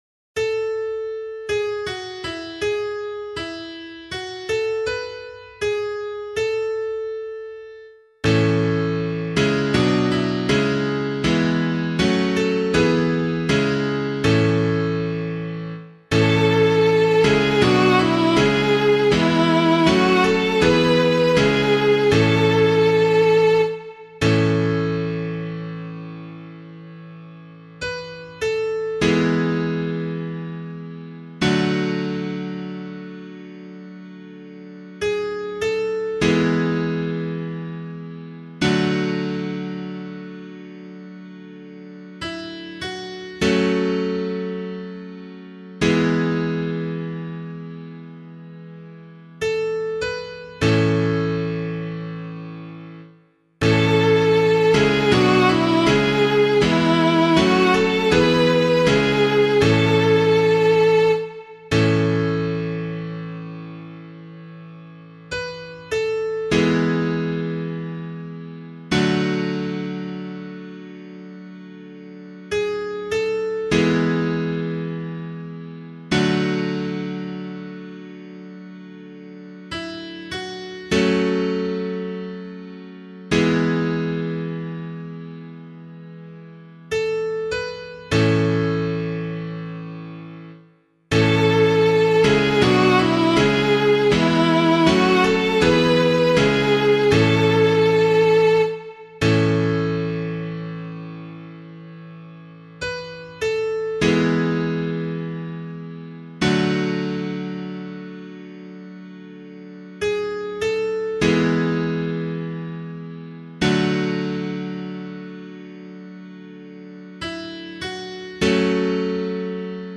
015 Lent 3 Psalm B [LiturgyShare 8 - Oz] - piano.mp3